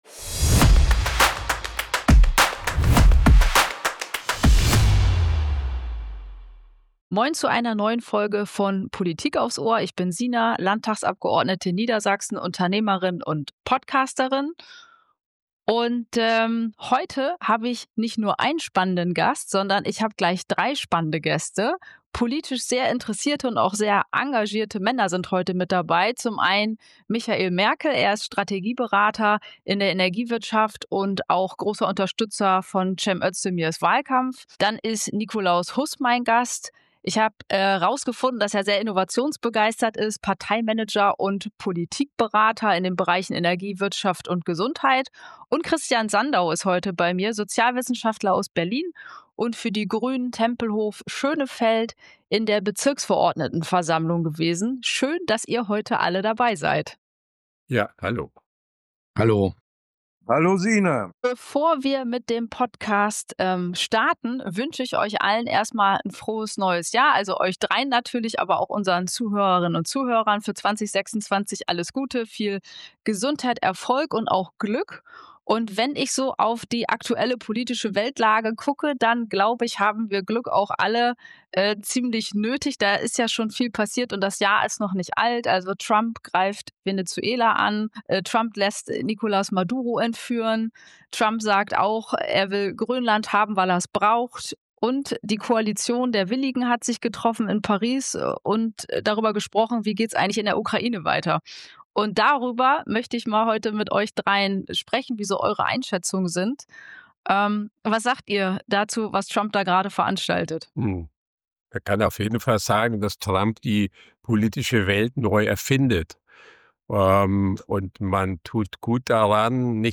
Weltpolitik im Vierergespräch und die Frage, was 2026 uns bringt?